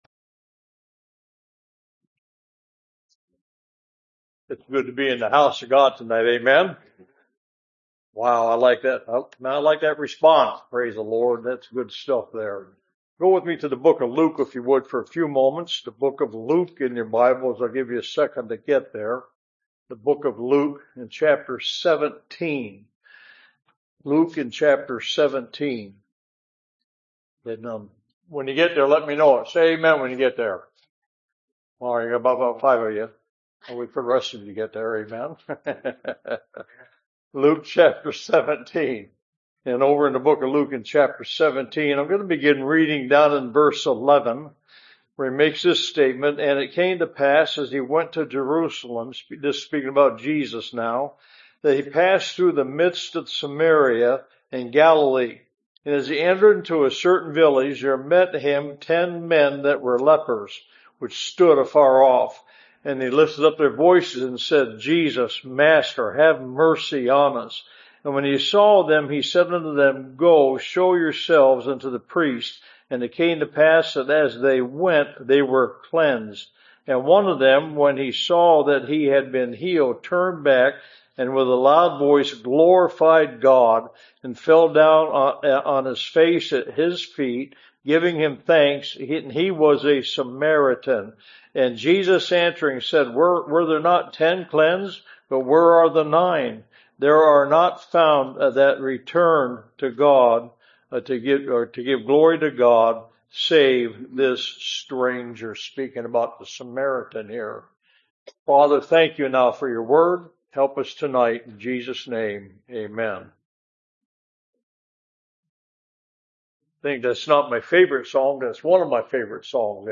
Revival Preachings Service Type: Revival